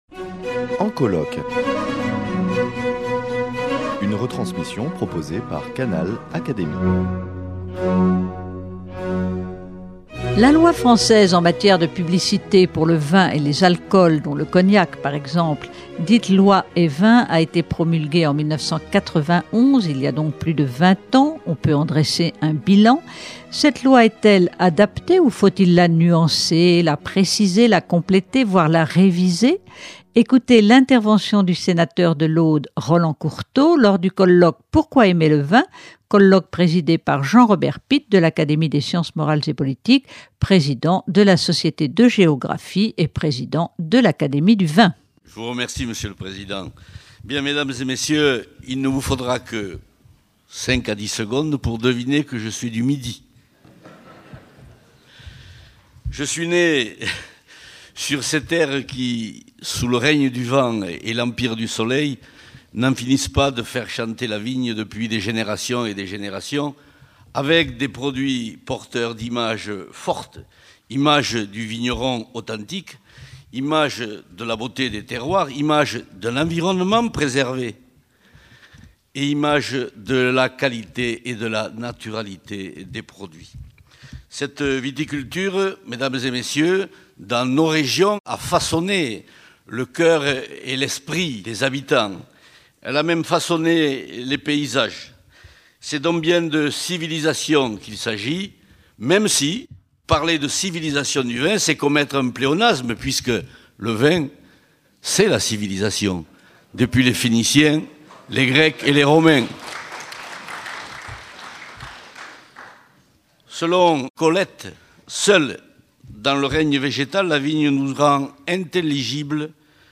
Ecoutez l’intervention du sénateur de l’Aude Roland Courteau dans son intervention au colloque « Pourquoi aimer le vin ? », colloque présidé par Jean-Robert Pitte, de l’Académie des sciences morales et politiques, président de la Société de géographie et président de l’Académie du vin.
Avec un accent du midi qui révèle son ancrage local, le sénateur Courteau, auteur d’une proposition de loi sur le vin, patrimoine culturel de la France, et fondateur de l’ANEV (Association nationale des Elus du Vin) a dénoncé les lobbies antivin (associations qu’il dit subventionnées pour éliminer tout alcool) et a passionnément défendu ses convictions : mieux informer sur le vin n’incite pas à consommer plus.